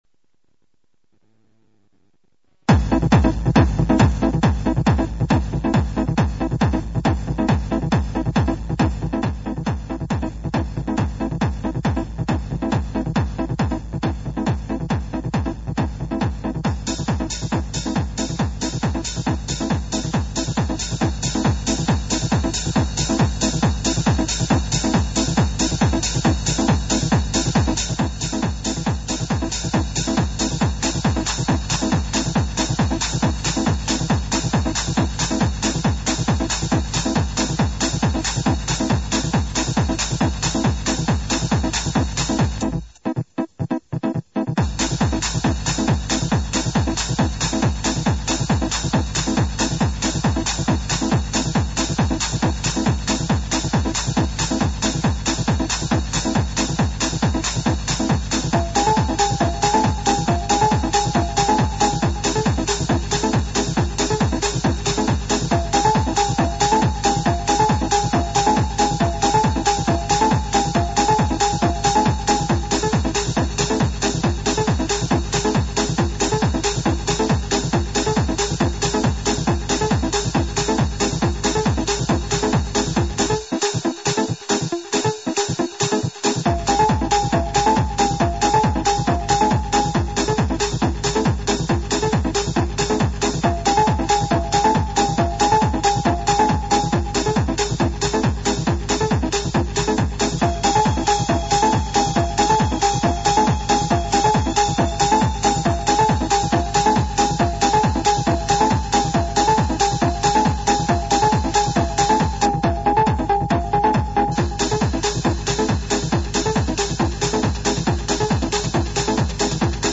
Anoche comenzamos el programa, que fue tan tranquilo que parecía que esto no era Oficina en Varsovia, nuevamente con noticias sobre el Festival Internacional de Acción Artística Sostenible SOS 4.8 , ya que se han confirmado nuevas incorporaciones a su cartel.